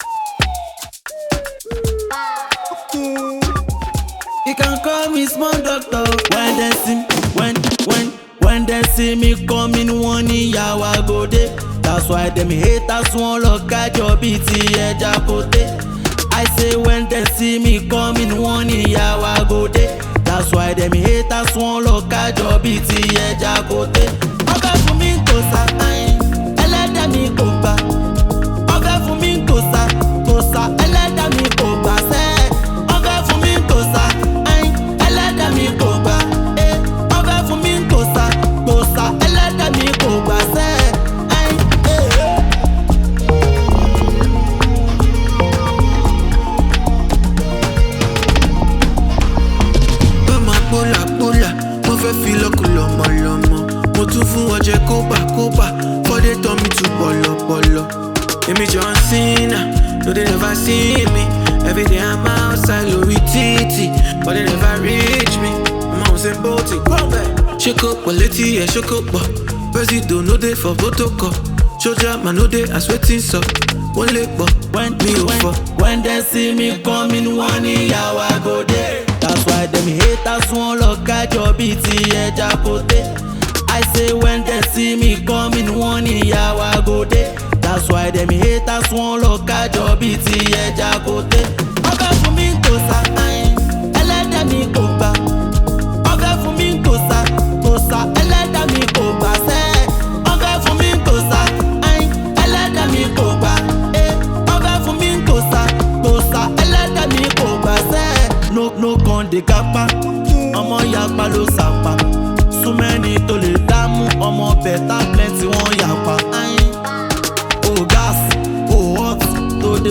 Nigerian Fuji, an Afro-pop